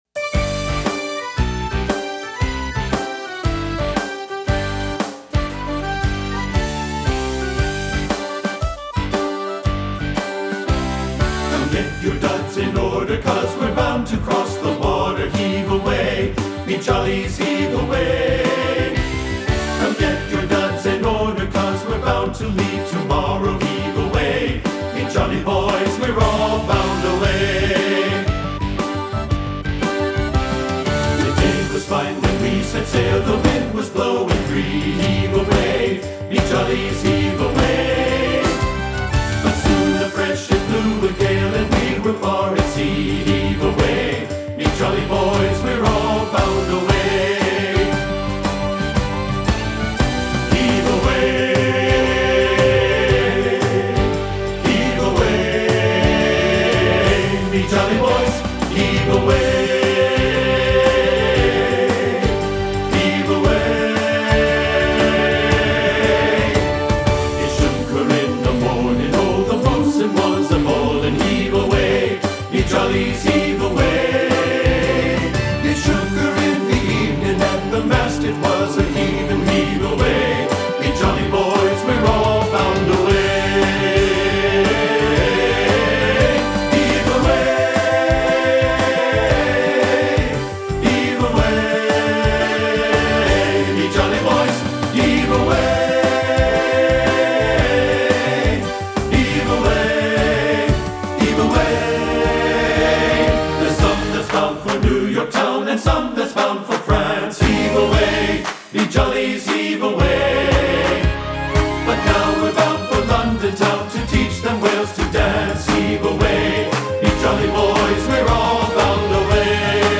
Choral Male Chorus
Sea Shanty